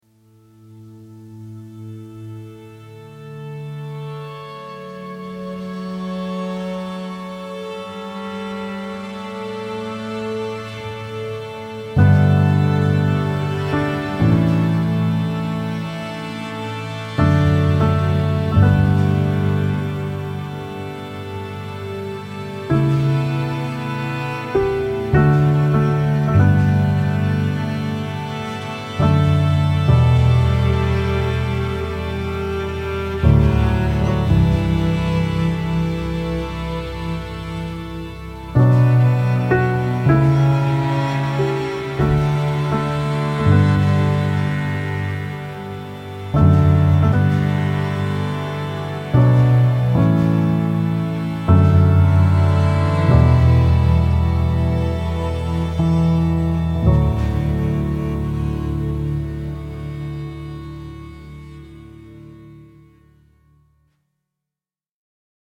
Innovative cello library